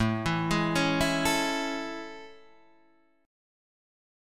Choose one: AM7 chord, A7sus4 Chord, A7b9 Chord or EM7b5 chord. AM7 chord